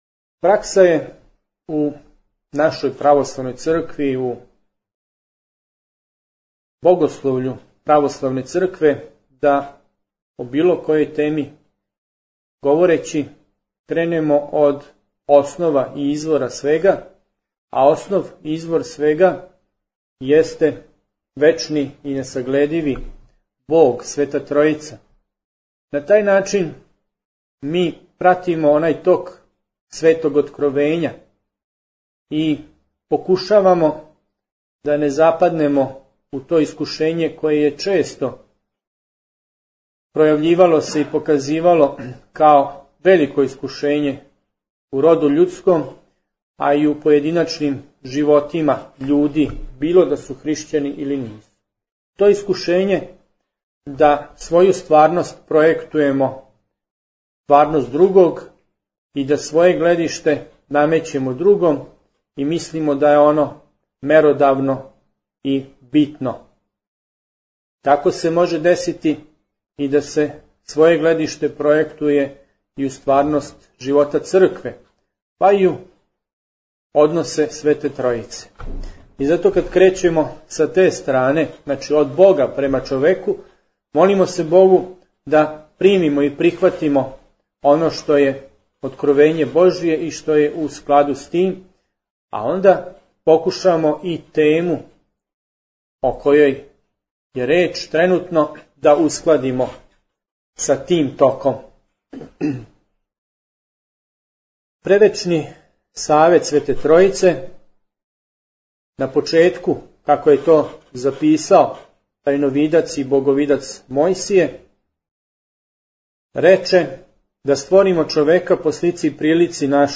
У недељу, 21. марта 2010. године, одржана је Трибина на Учитељском факултету у Сомбору на тему Хришћански брак у светој Литургији.
Звучни запис предавања